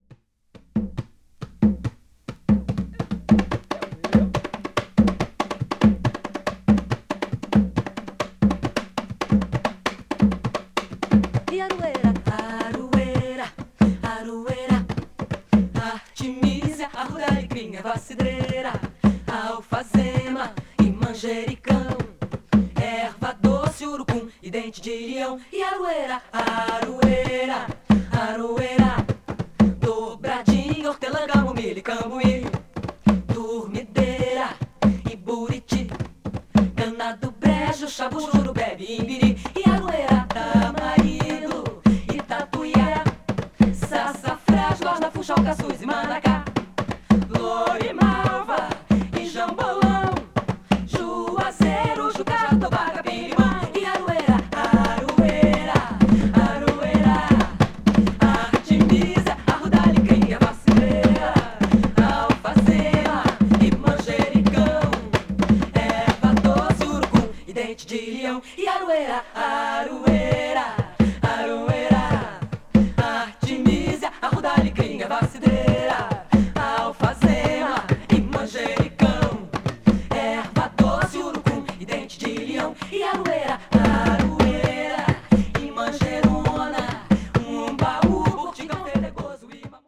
acid folk   brazil   mpb   psychedelic   world music